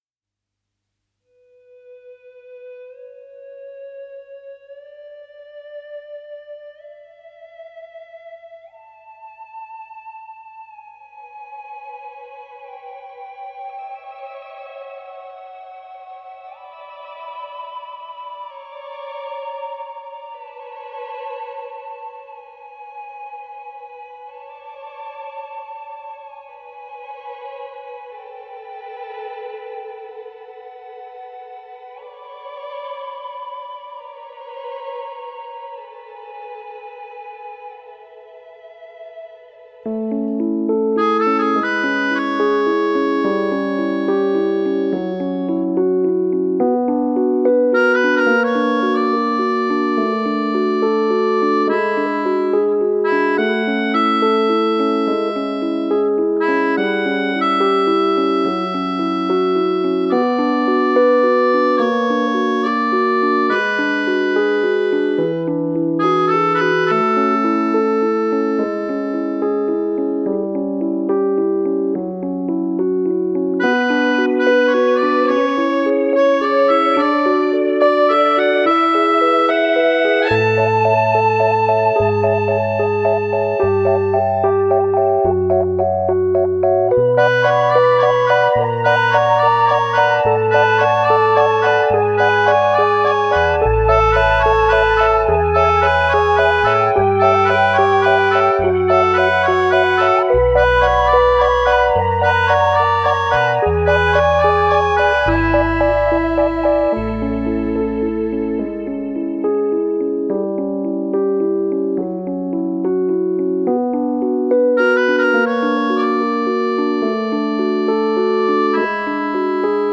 Genre: Indie, Alternative Rock, Original Soundtrack